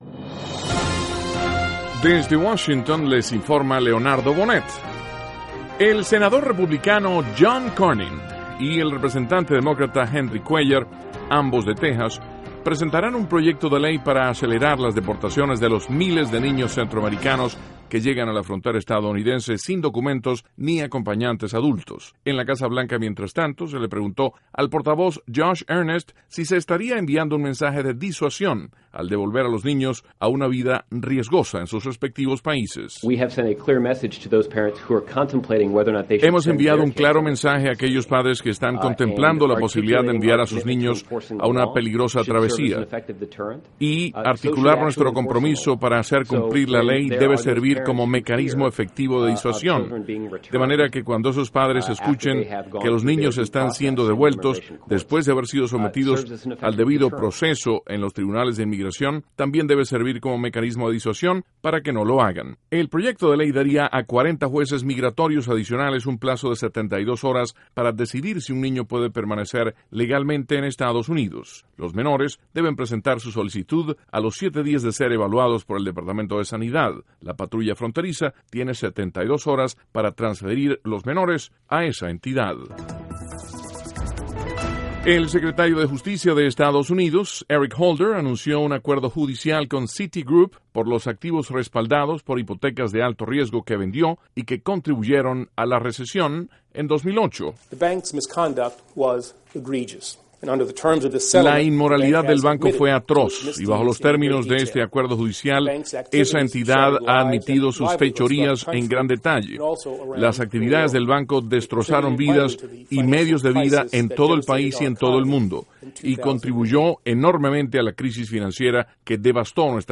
NOTICIAS - LUNES 14 DE JULIO, 2014
Duración: 3:03 Contenido: 1.- La Casa Blanca pretende enviar mensaje de disuasión a los padres de niños que son enviados a la frontera estadounidense. (Sonido Earnest) 2.- Departamento de Justicia de Estados Unidos aplica mayor sanción por fraude a Citigroup. (Sonido Eric Holder) 3.- Francia celebra Día de la Bastilla.